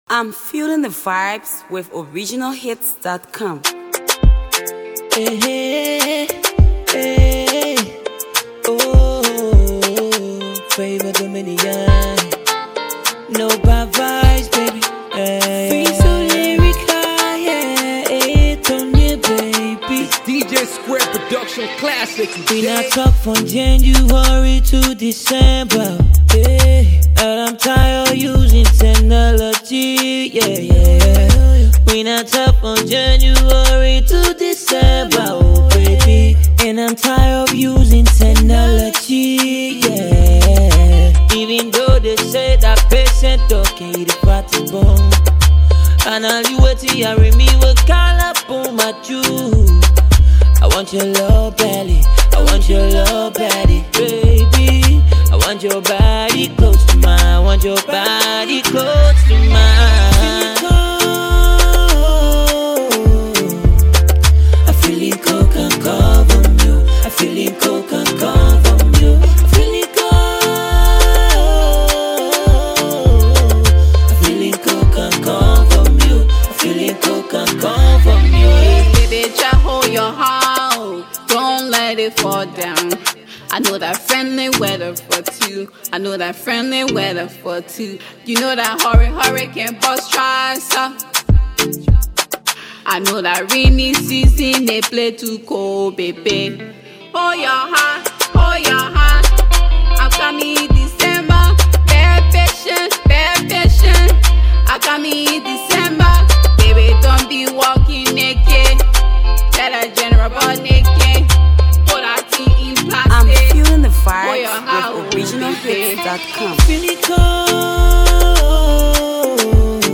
festive season jam
Afro Pop